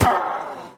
mob / witch / death2.ogg
death2.ogg